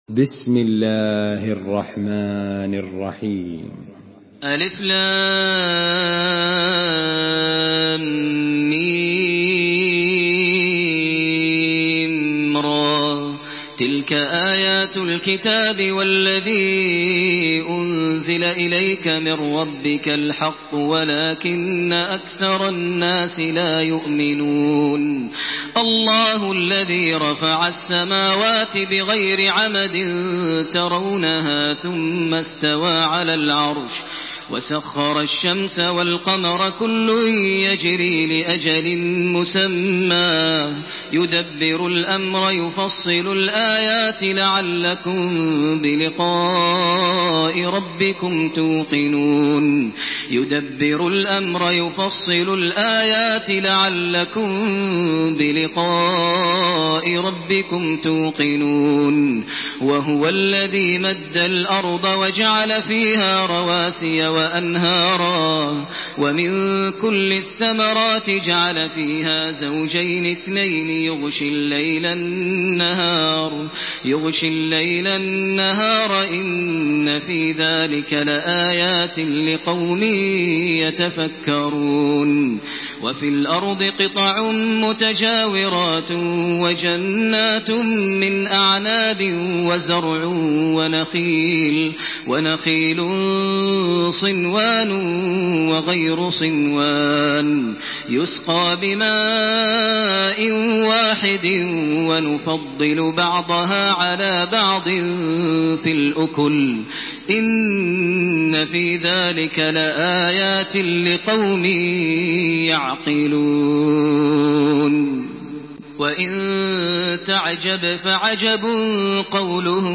Surah Ar-Rad MP3 by Makkah Taraweeh 1428 in Hafs An Asim narration.